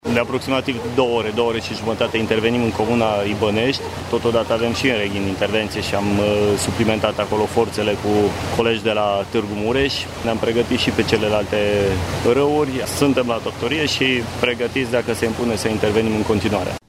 La fața locului s-au deplasat și prefectul Județului Mureș Cristina-Mara Togănel și Inspectorul şef al ISU ”Horia” Mureș Colonel Călin-Ioan Handrea: